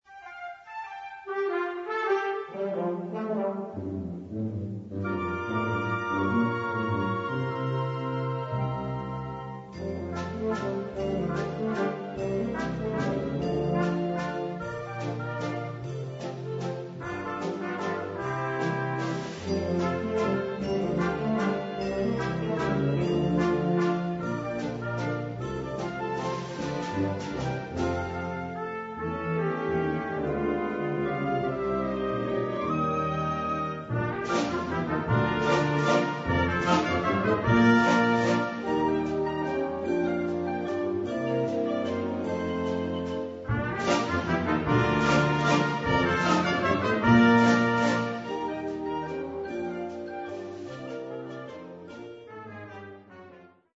Gattung: Walzer
Besetzung: Blasorchester
It is elegant, intricate, and emotional.
Flute 1/2
Oboe
Bassoon
Tuba
Timpani
Percussion 1 (bells, xylophone)
Percussion 2 (snare drum, bass drum)